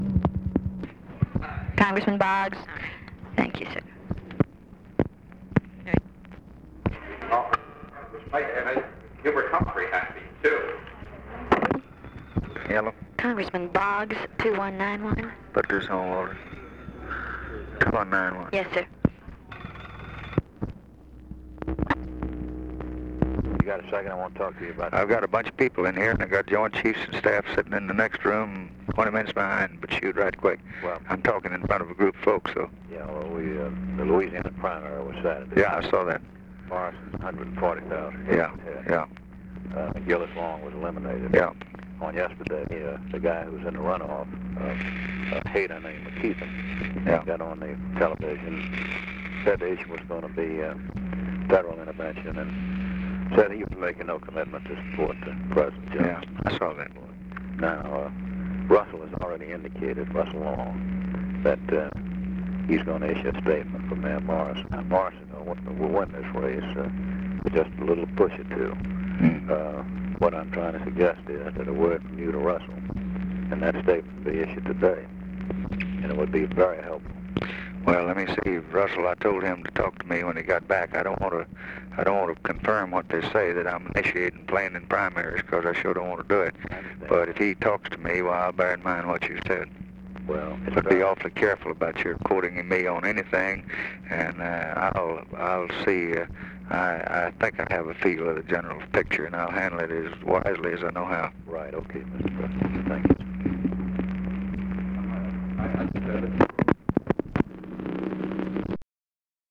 Conversation with HALE BOGGS, December 9, 1963
Secret White House Tapes